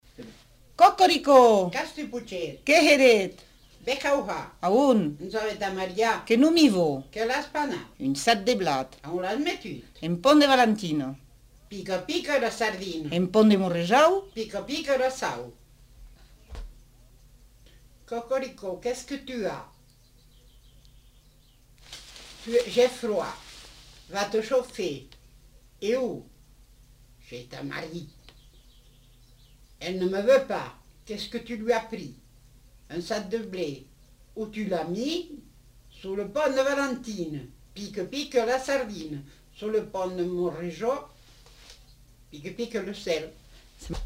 Lieu : Montauban-de-Luchon
Genre : forme brève
Effectif : 2
Type de voix : voix de femme
Production du son : récité
Classification : mimologisme